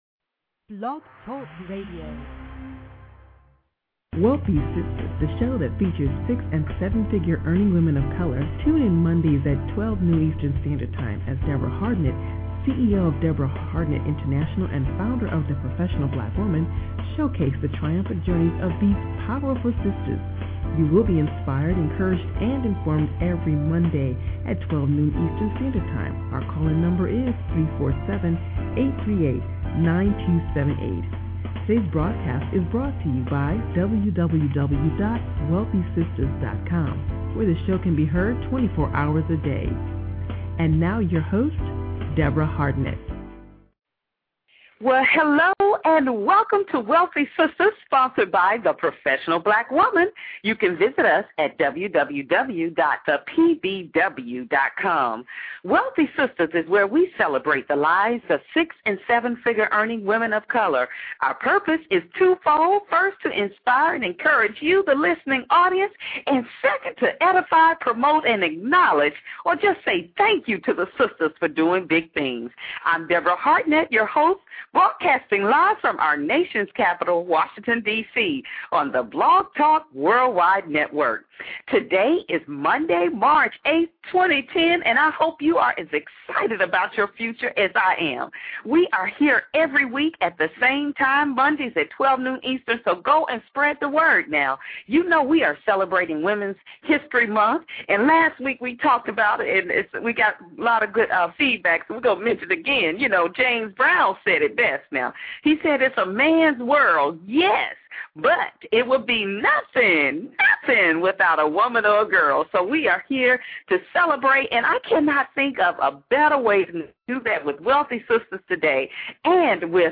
Wealthy Sistas® Entreprenuer & Motivational Speaker Returns Live!